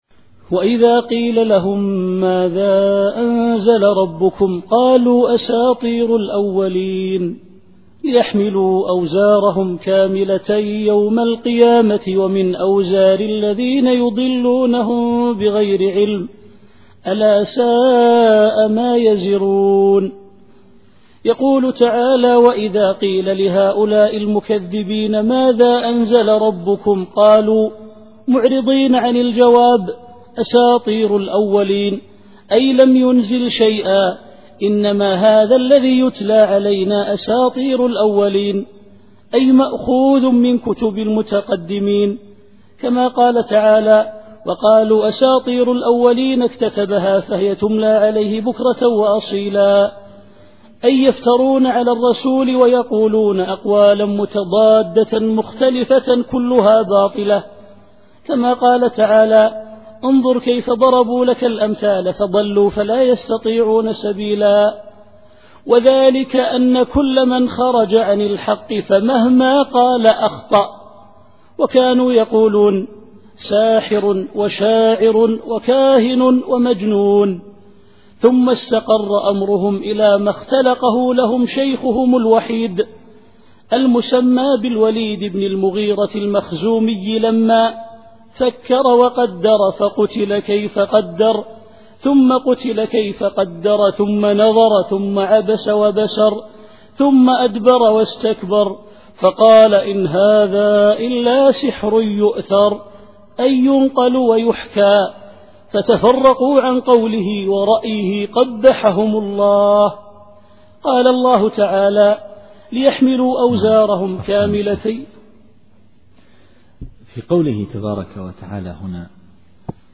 التفسير الصوتي [النحل / 24]